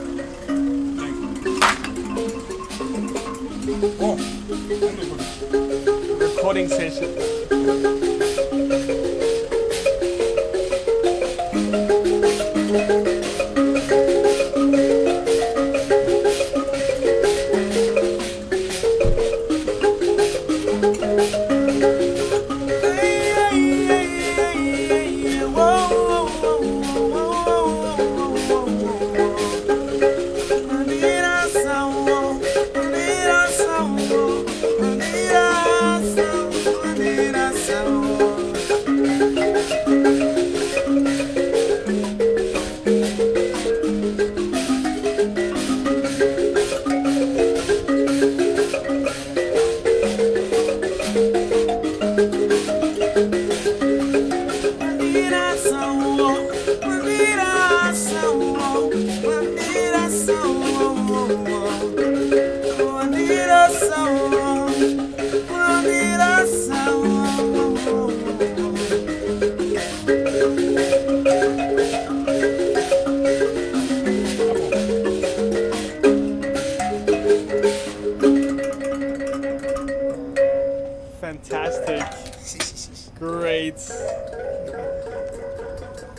African thumb piano
96183-african-thumb-piano.mp3